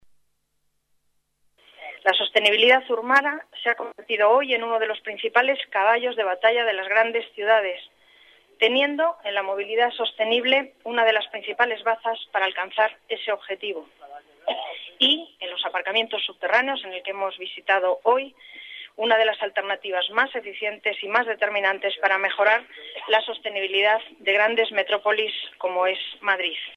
Nueva ventana:Declaraciones de la delegada de bras, Paz González: Apuesta por Sostenibilidad